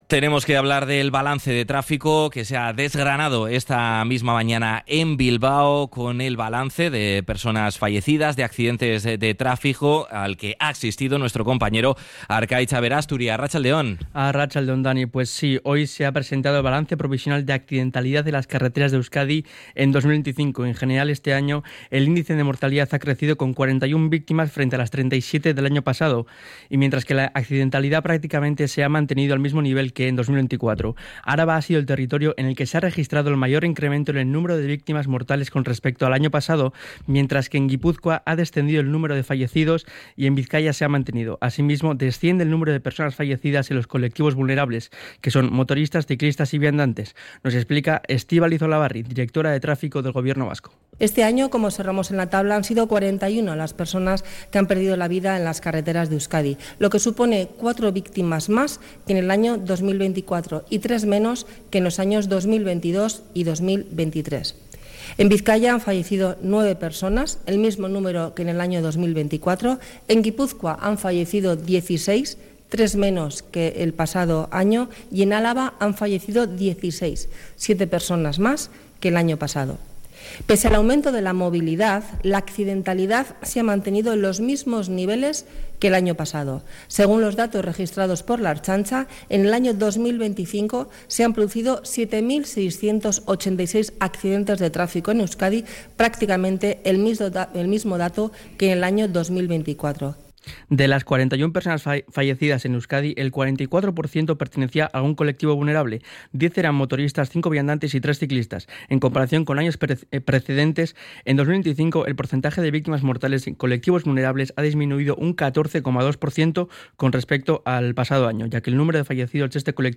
Estibaliz Olabarri en la presentación del balance provisional de la accidentalidad en las carreteras del Euskadi en 2025 / RADIO POPULAR - HERRI IRRATIA